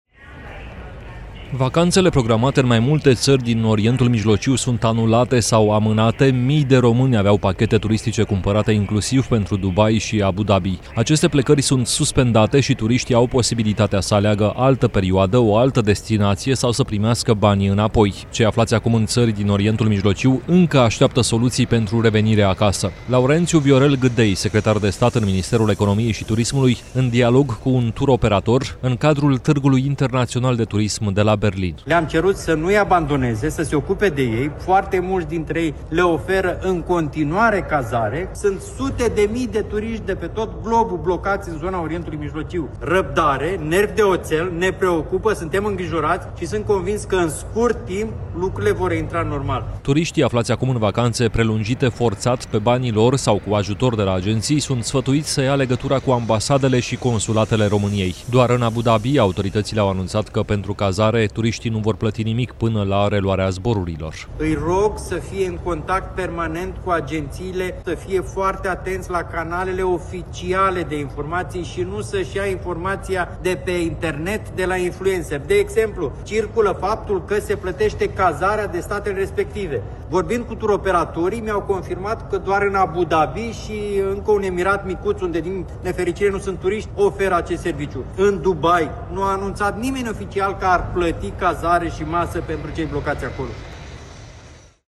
Laurențiu-Viorel Gîdei, secretar de stat în Ministerul Economiei și Turismului, aflat la Târgul Internațional de Turism de la Berlin: